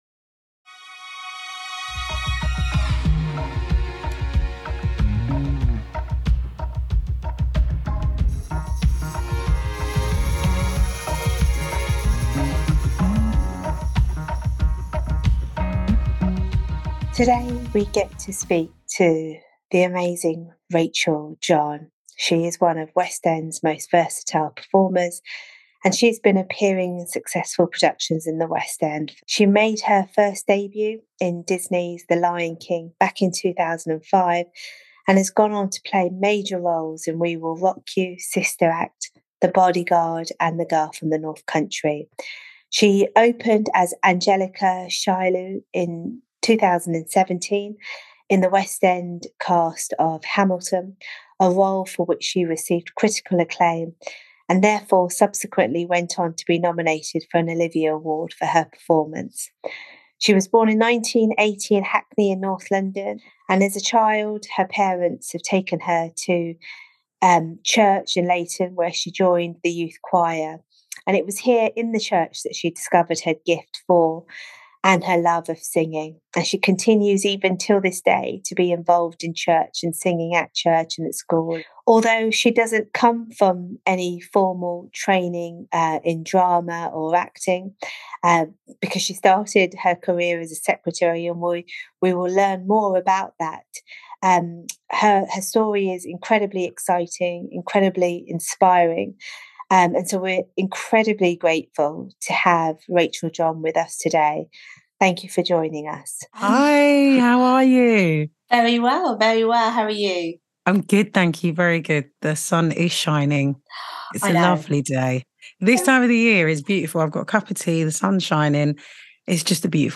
Grab a seat with us as we chat to Rachel John, West End actress, singer, and storyteller whose faith has shaped every step of her journey.